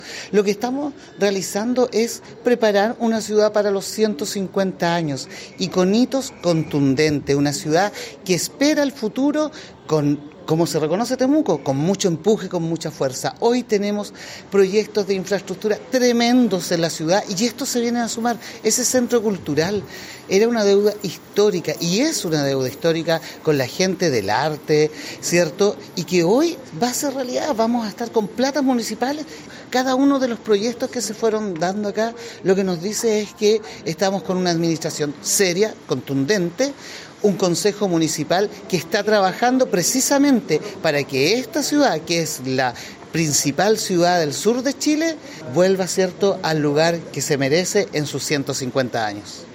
Esteban-Barriga-concejal-Temuco.mp3